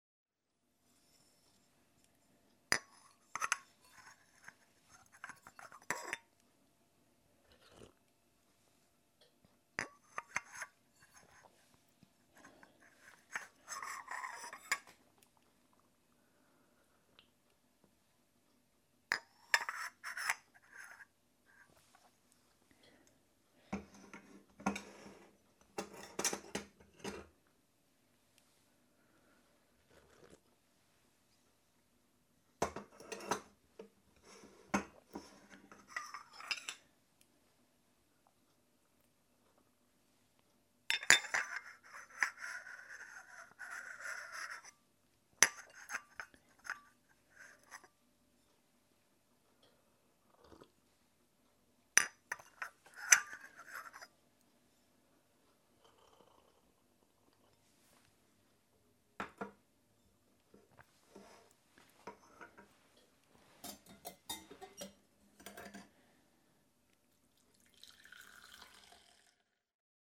This is the sound of tea being made